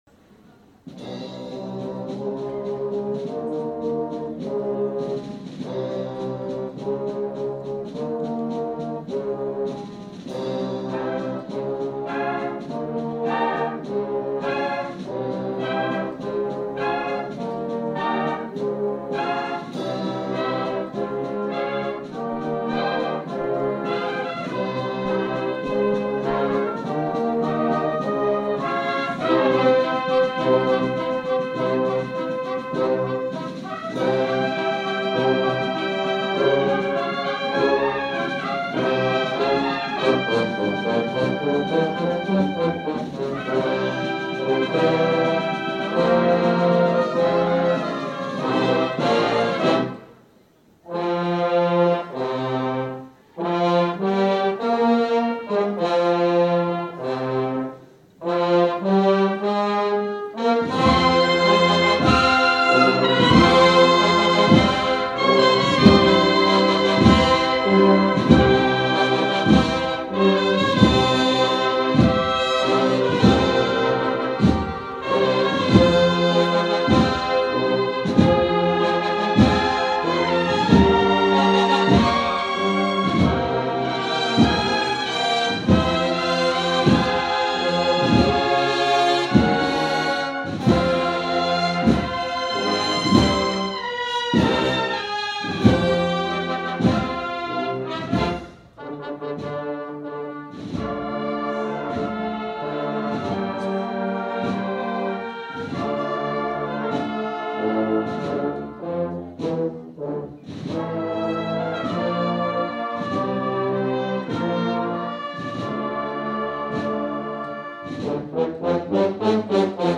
La Banda de Cornetas y Tambores de Jesús Despojado ofreció el sábado un concierto en el exterior del templo del Monasterio de las Comendadoras de Santiago que generó mucha expectación, ya que se estrenó la marcha ‘Mara’ compuesta por Ignacio García y dedicada a la Virgen de la Amargura.
Se compone de fragmentos de conocidas marchas de palio que suenan de forma original con la identidad de los instrumentos de una banda de cornetas y tambores, lo que sorprendió a muchos por el resultado compositivo e interpretativo por parte de la formación musical.
Ser Cofrade ofrece aquí la posibilidad de escuchar este ‘popurrí’ de marchas de palio interpretado por la banda de cornetas y tambores de Jesús Despojado: